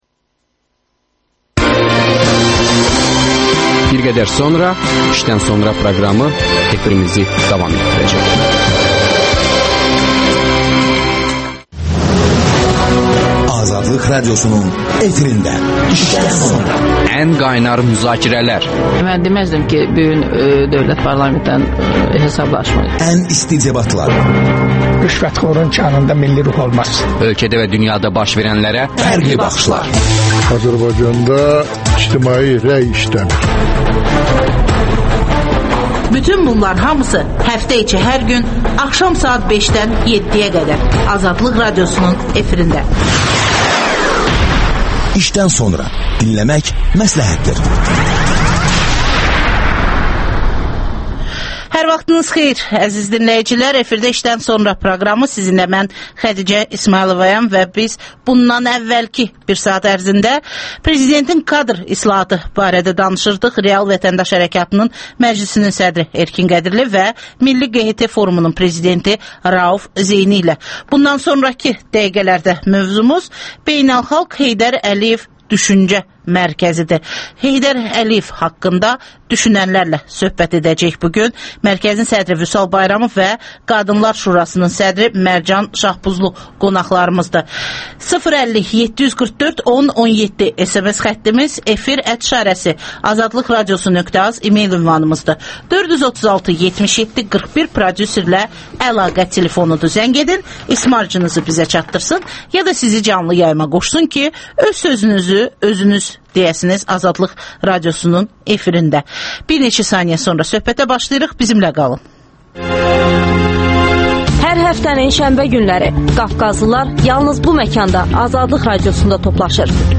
İşdən sonra - Beynəlxalq Heydər Əliyev Düşüncə Mərkəzinin təsisçiləri canlı efirdə...